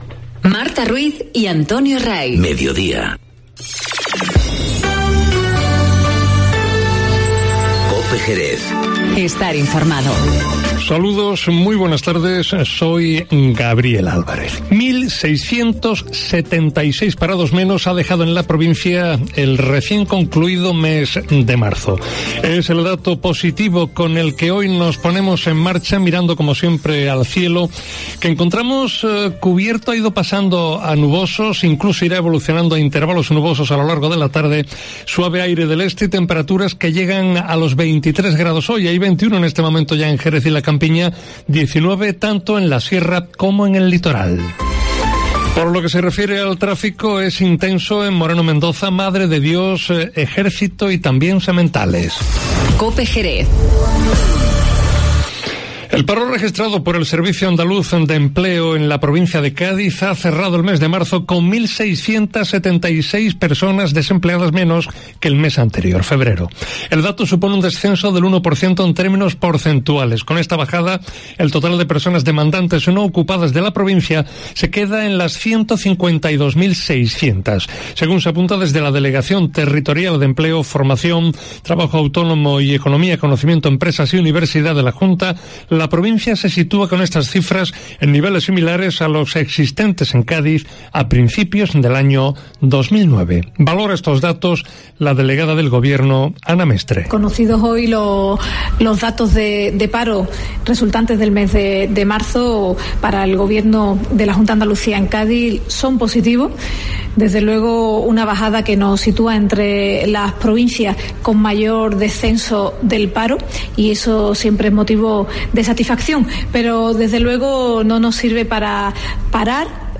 Informativo Mediodía COPE Jerez